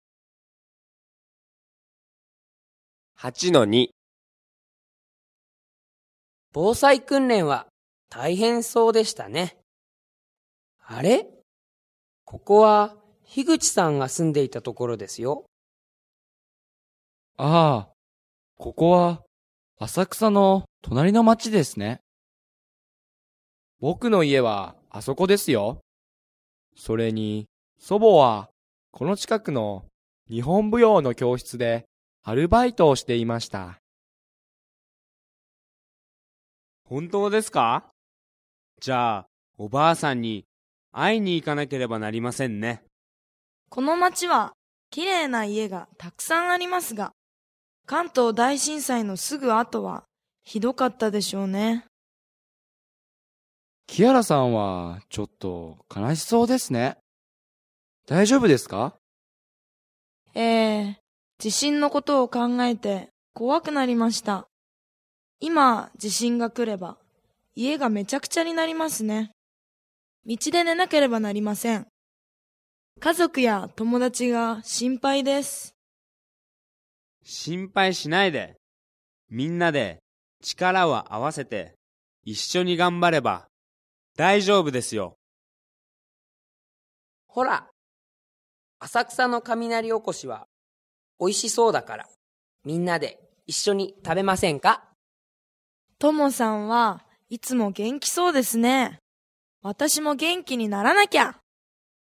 Ch8 Section2 Dialogue
8-2_IJ_Ch8 Sec2-Dialg.mp3